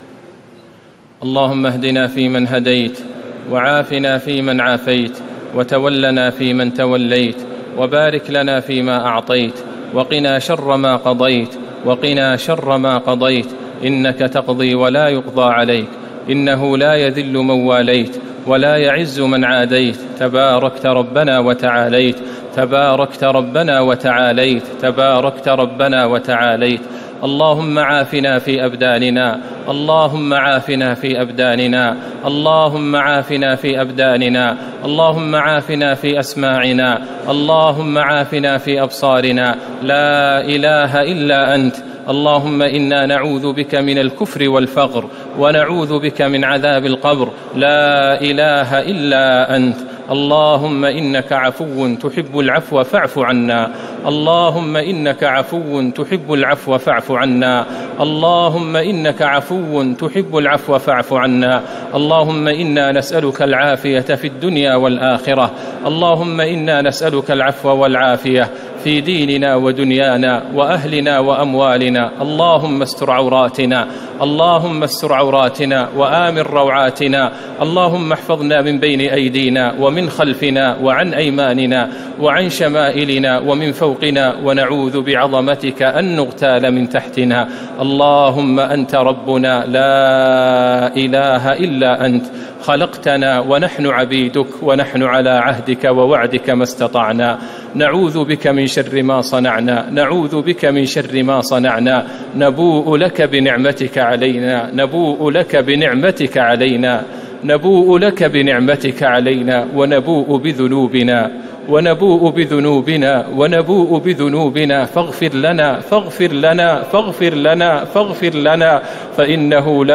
دعاء القنوت ليلة 4 رمضان 1439هـ | Dua for the night of 4 Ramadan 1439H > تراويح الحرم النبوي عام 1439 🕌 > التراويح - تلاوات الحرمين